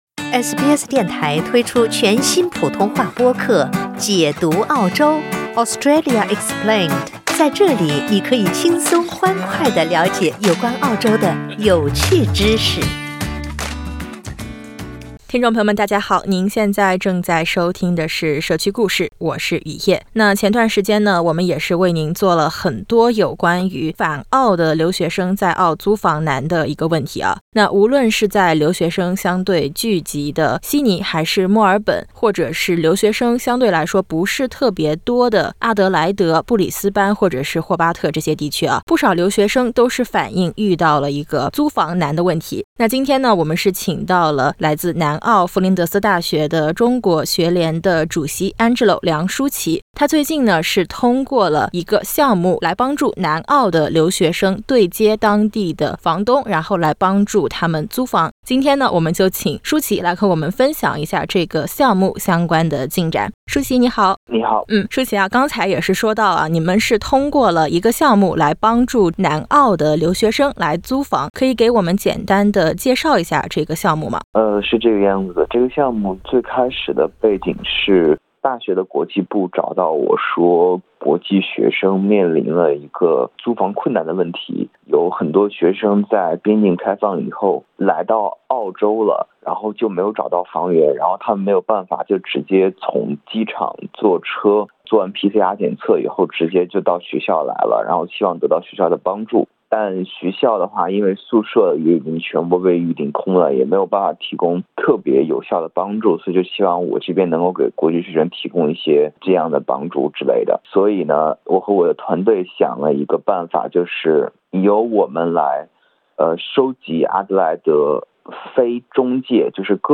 上月中旬，南澳弗林德斯大学的中国学联听闻有留学生面临租房困境，联合南澳大学的中国学联组织活动，线上号召阿德莱德的房东分享空置房源，再对接有需求的学生，此举帮不少留学生解决了燃眉之急（点击封面音频，收听完整采访）。